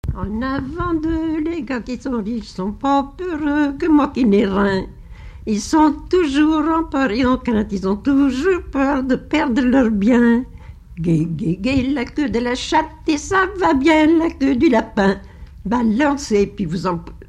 Note avant-deux
Couplets à danser
Pièce musicale inédite